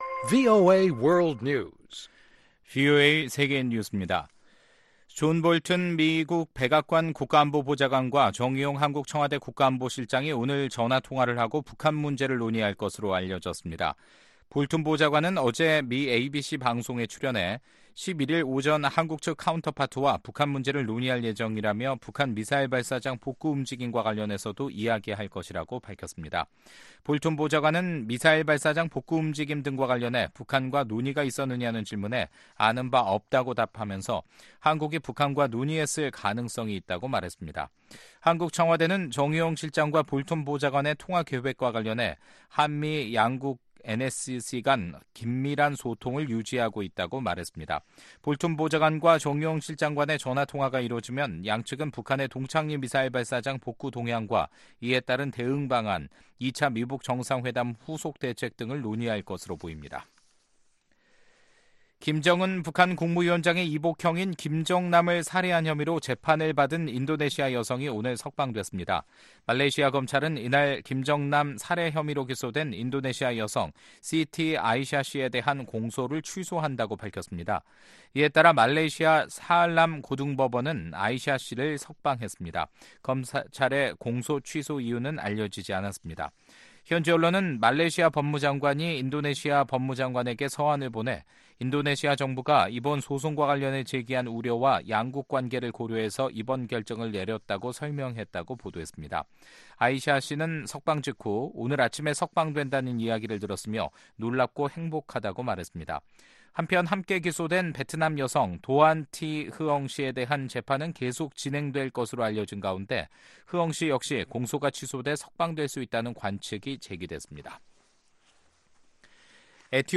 VOA 한국어 간판 뉴스 프로그램 '뉴스 투데이', 2019년 3월 11일 3부 방송입니다. 존 볼튼 미 백악관 국가안보보좌관은 북한 ‘동창리 미사일 발사장’의 최근 복구 움직임과 관련해 주시하고는 있지만 추측을 하지는 않겠다고 밝혔습니다. 두 차례의 미-북 정상회담 이후에도 북한은 불법 무기 프로그램 폐기를 위한 구체적 행동이 전혀 없다며 최대압박을 지속해야 한다고 코리 가드너 상원의원이 말했습니다.